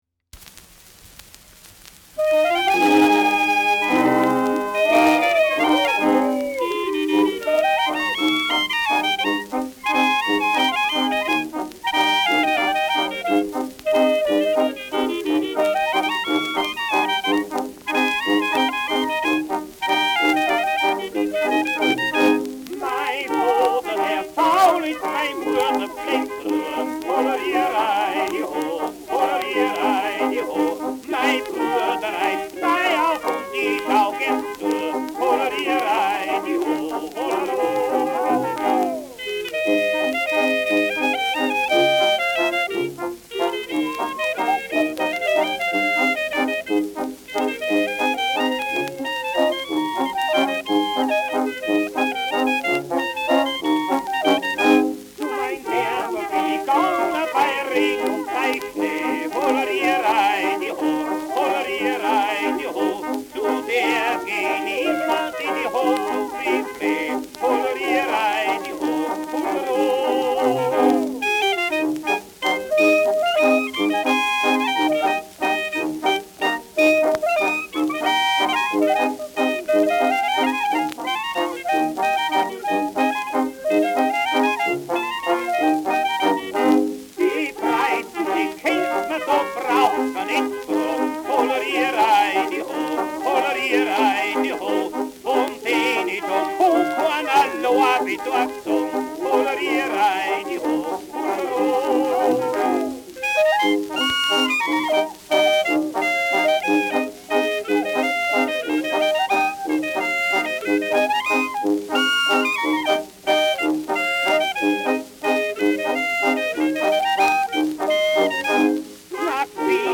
Bei uns dahoam : Ländler, I. Teil : alte bayrische Volksweise [Bei uns daheim : Ländler, 1.
Schellackplatte
„Hängen“ bei 2’02’’ : präsentes Knistern : leichtes Rauschen
Isartaler Bauernkapelle (Interpretation)
[München] (Aufnahmeort)